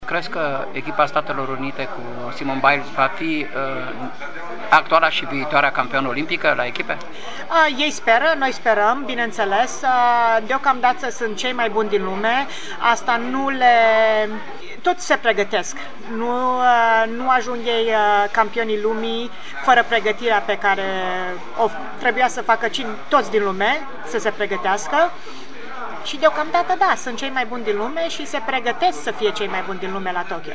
Stabilită în SUA din 1991, Daniela Silivaş (foto, în centru), una dintre cele mai mari gimnaste din istorie, s-a întors acasă, la Deva, la final de an și a acceptat să vorbească, pentru Radio Timișoara, despre ce a însemnat 2019 pentru gimnastica din România și despre cum crede vor arăta Jocurile Olimpice de la Tokyo, din acest an.